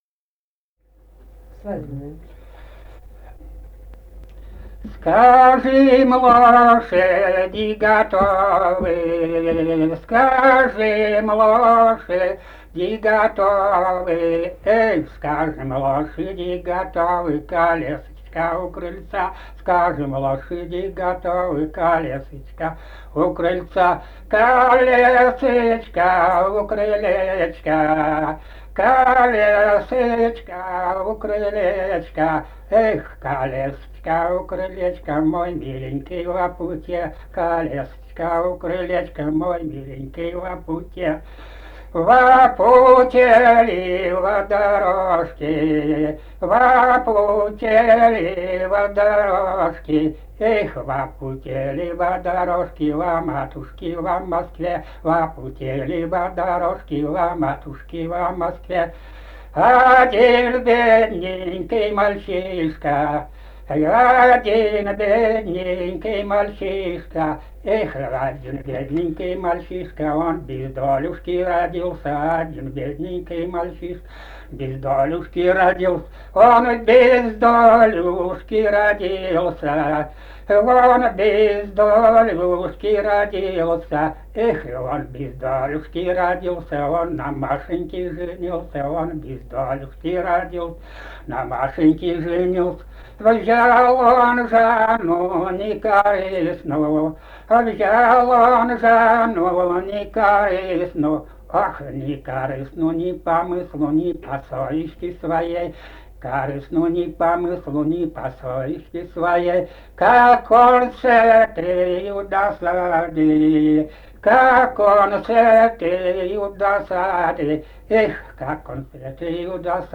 полевые материалы
«Скажем, лошади готовы» (плясовая на свадьбе).
Казахстан, г. Уральск, 1972 г. И1312-05